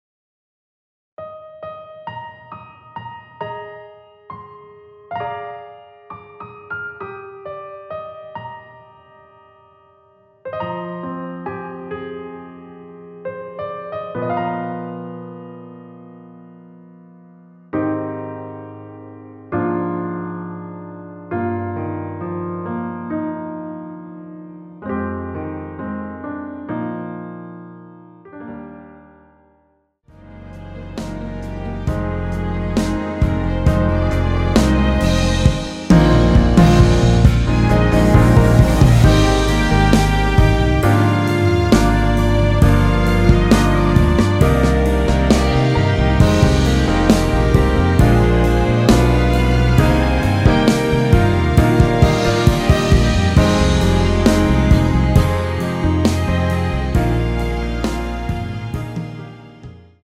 원키에서(-3)내린 MR입니다.
Eb
앞부분30초, 뒷부분30초씩 편집해서 올려 드리고 있습니다.